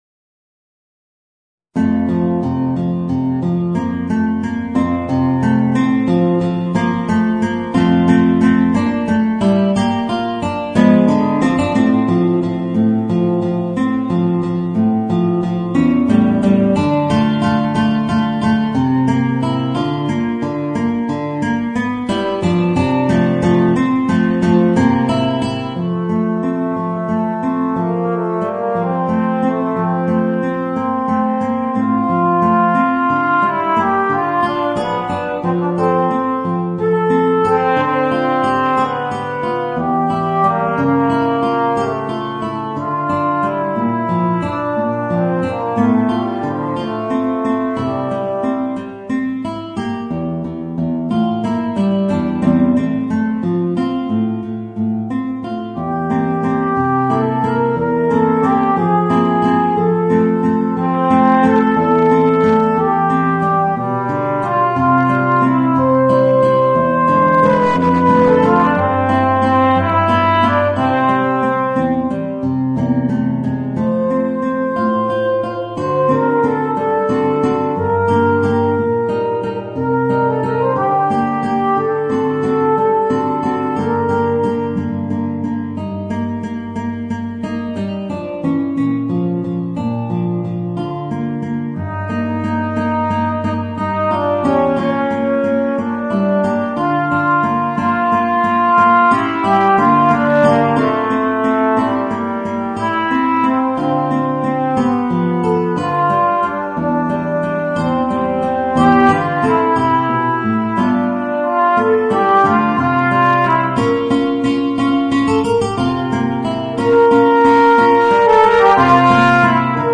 Voicing: Alto Trombone and Guitar